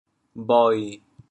bhoi7.mp3